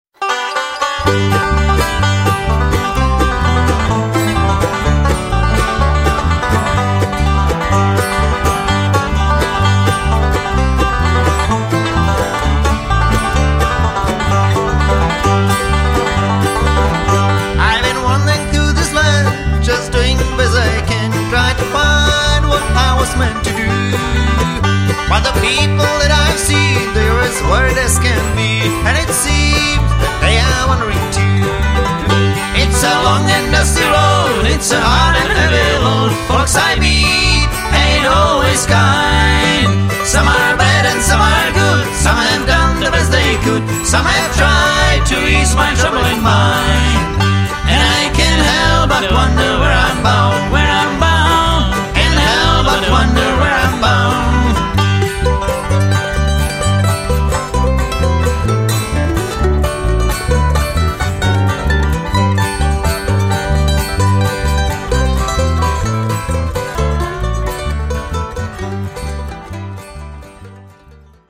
lead
tenor
baritone